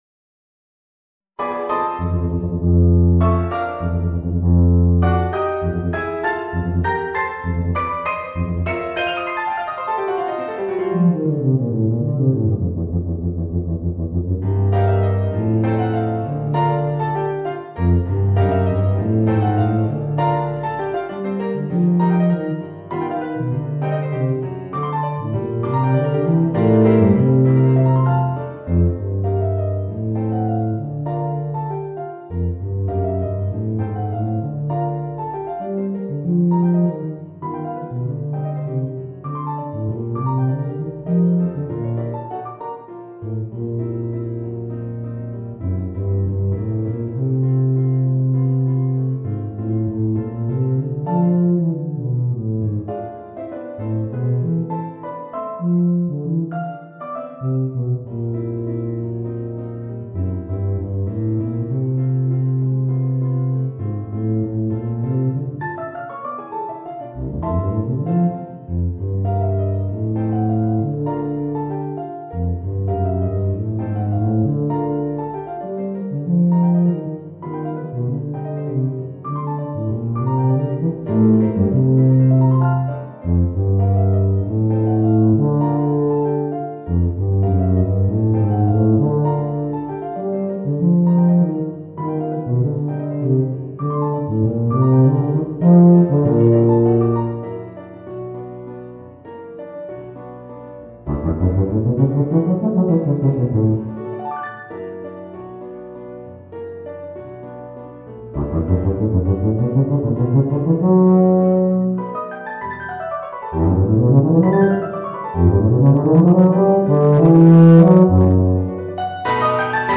Voicing: Bb Bass and Piano